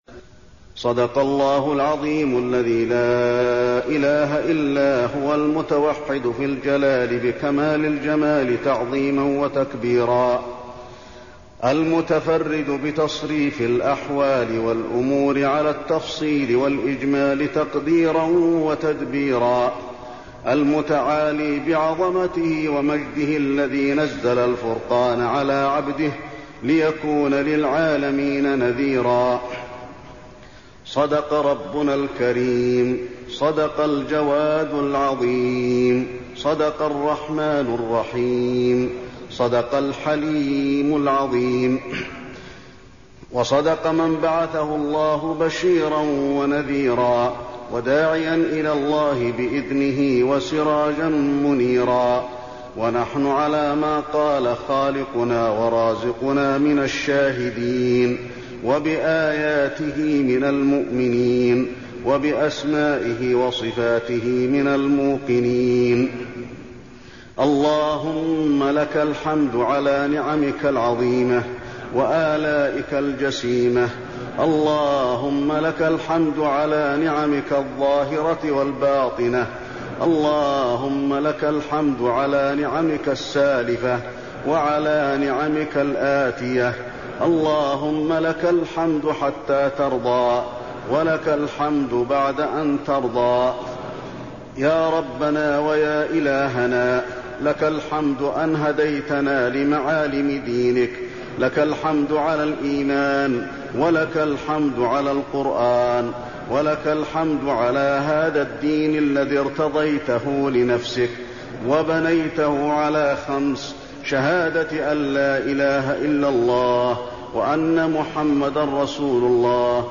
الدعاء
المكان: المسجد النبوي الدعاء